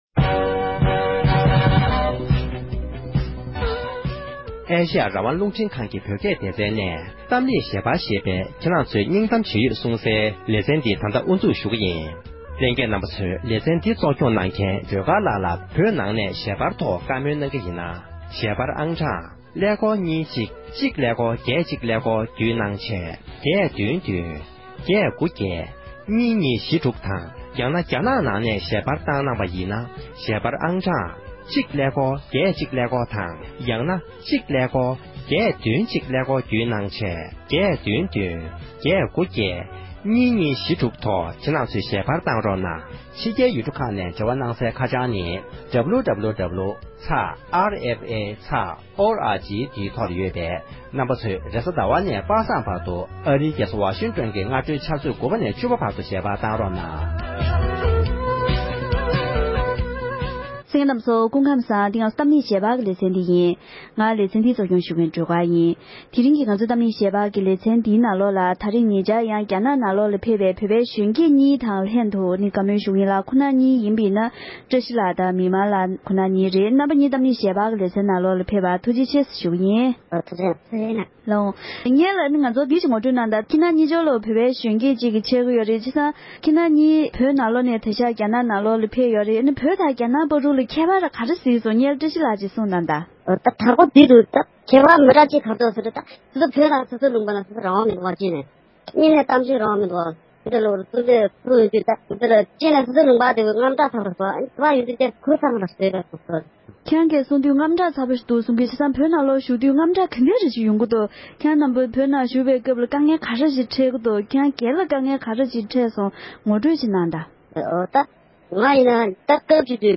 བོད་ནང་གི་བོད་རིགས་གཞོན་སྐྱེས་ཚོས་བོད་ཀྱི་གནས་སྟངས་ལ་ལྟ་ཚུལ་ཇི་འདྲ་ཡོད་མིན་ཐད་བགྲོ་གླེང༌།